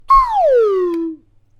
jazzoflute.mp3